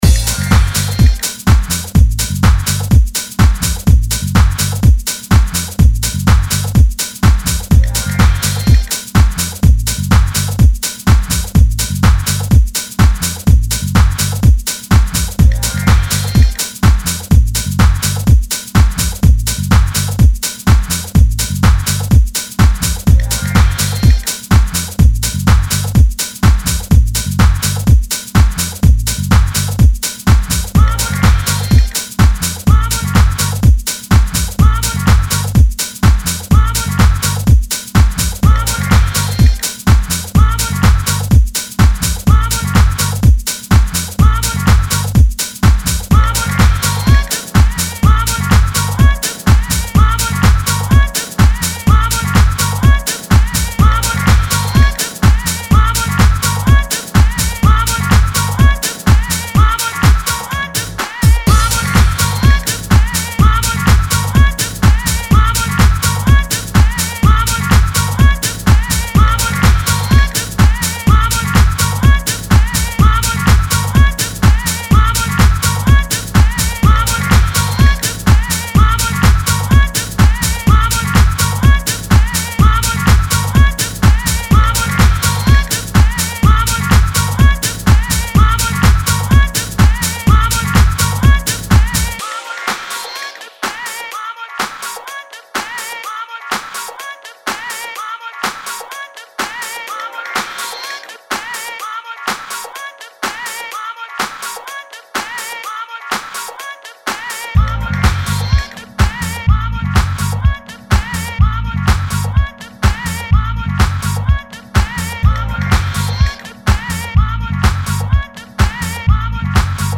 these tracks hum with hardware energy and soul.